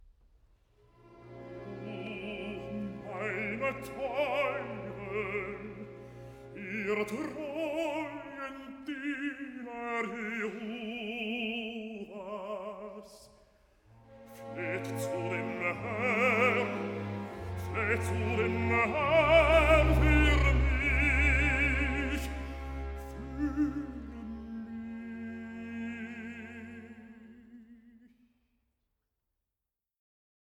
Aria (Jeremias)